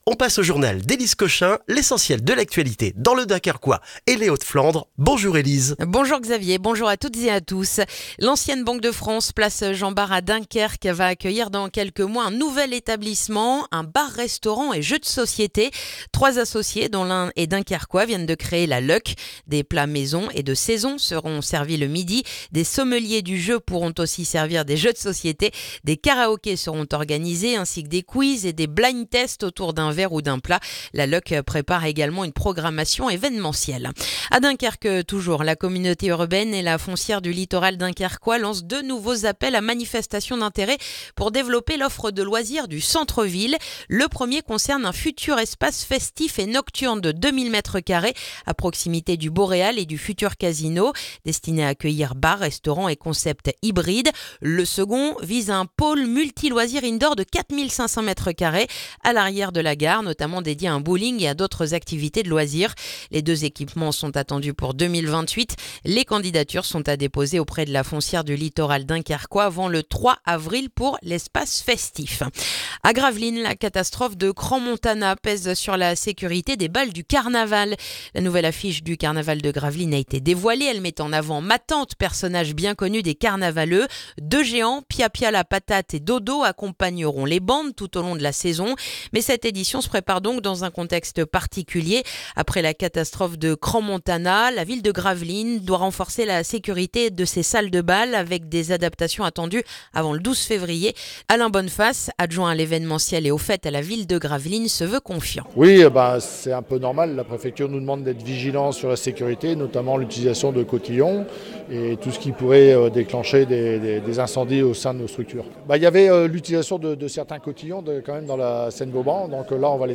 Le journal du mercredi du 4 février dans le dunkerquois